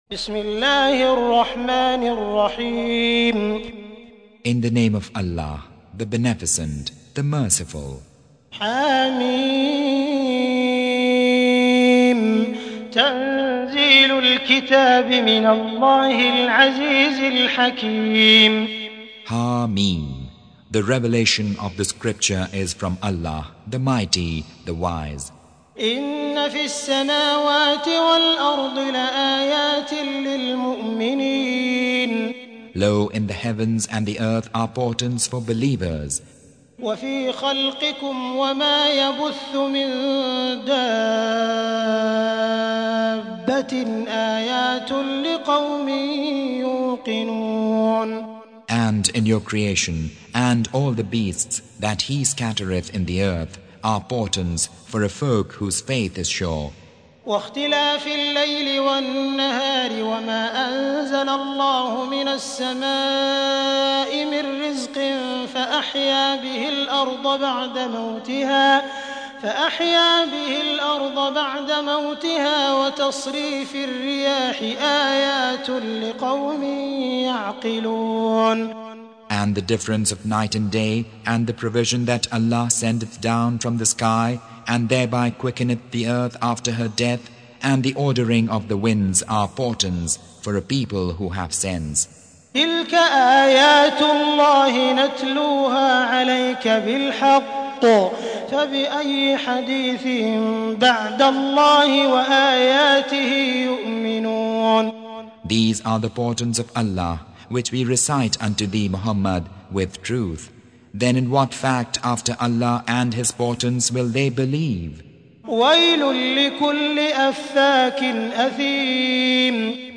Surah Repeating تكرار السورة Download Surah حمّل السورة Reciting Mutarjamah Translation Audio for 45. Surah Al-J�thiya سورة الجاثية N.B *Surah Includes Al-Basmalah Reciters Sequents تتابع التلاوات Reciters Repeats تكرار التلاوات